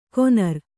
♪ konar